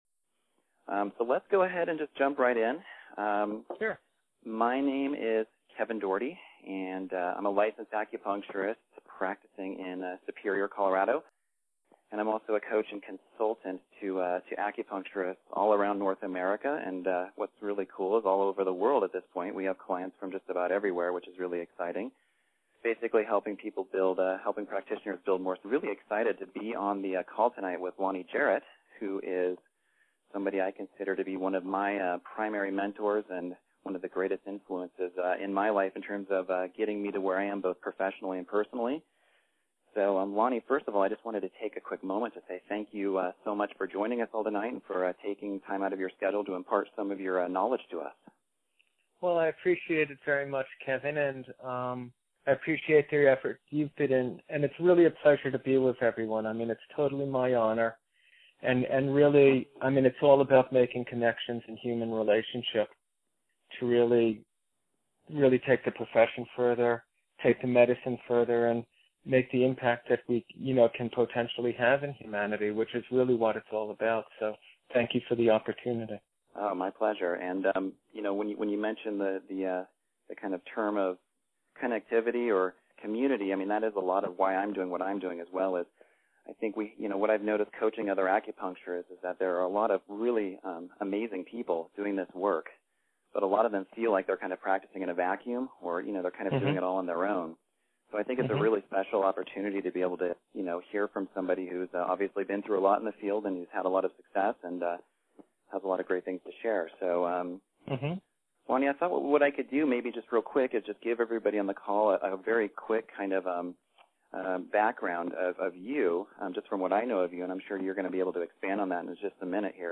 A few small audio gaps due to problems with the conference call recording service but the overall quality is good.